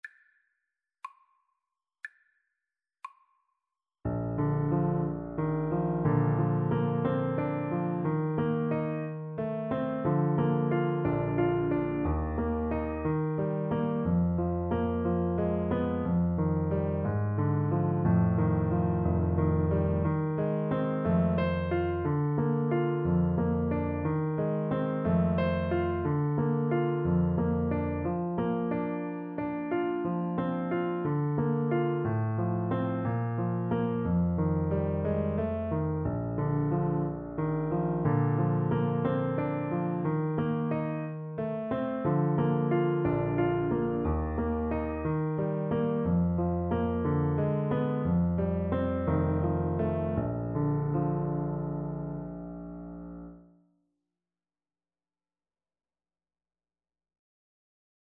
Play (or use space bar on your keyboard) Pause Music Playalong - Piano Accompaniment Playalong Band Accompaniment not yet available transpose reset tempo print settings full screen
Bb major (Sounding Pitch) G major (Alto Saxophone in Eb) (View more Bb major Music for Saxophone )
~ = 60 Andantino (View more music marked Andantino)